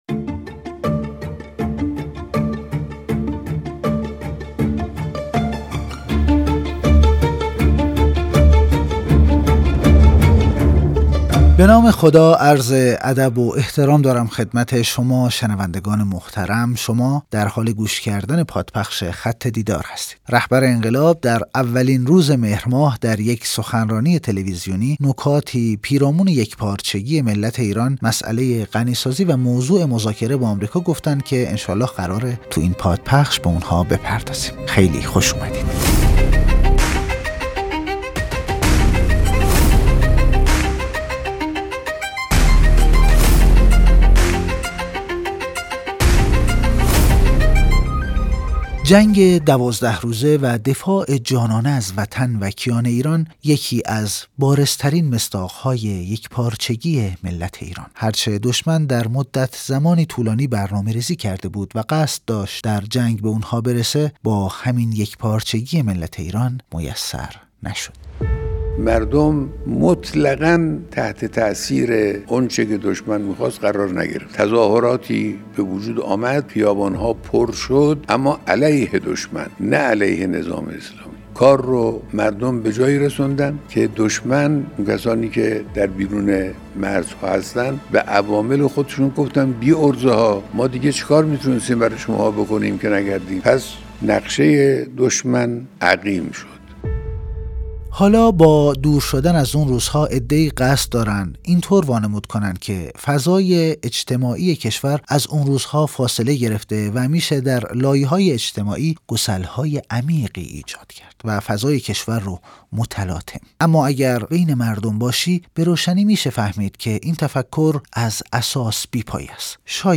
پادپخش خط دیدار - سخنرانی تلویزیونی رهبر معظم انقلاب اسلامی